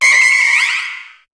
Cri de Méga-Nanméouïe dans Pokémon HOME.
Cri_0531_Méga_HOME.ogg